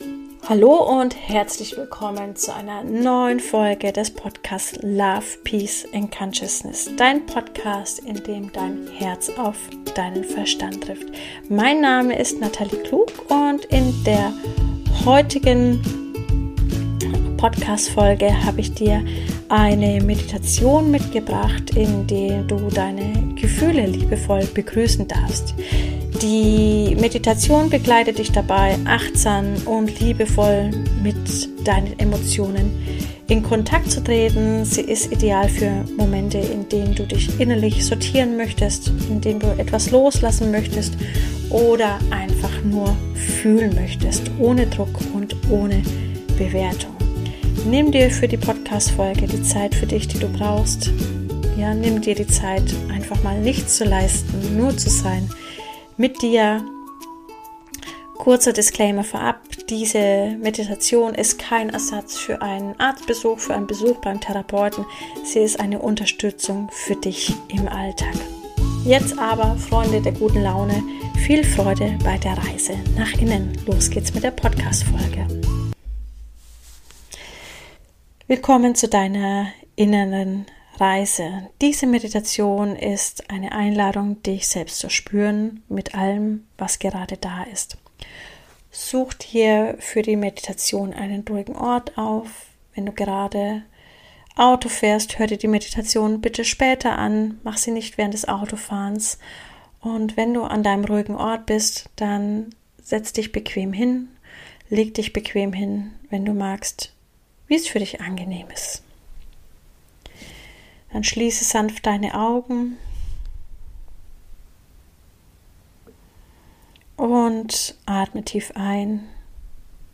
Meditation für deine emotionale Balance